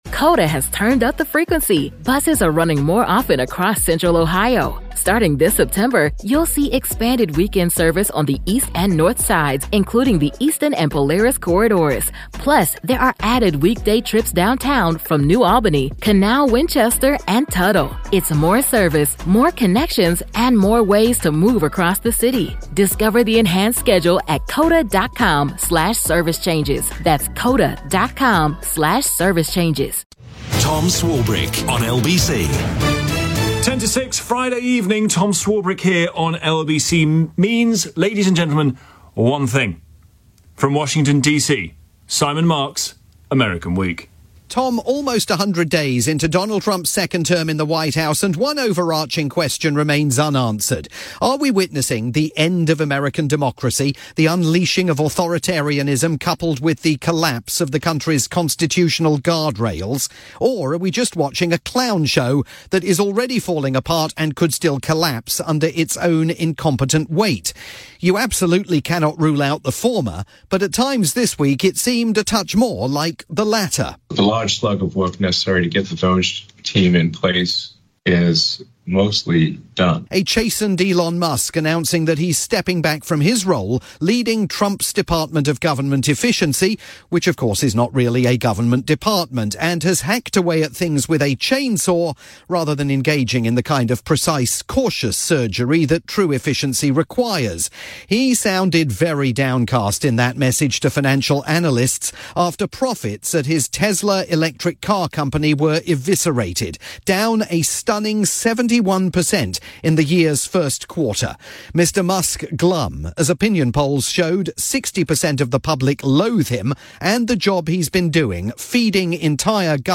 Friday drivetime programme on the UK's LBC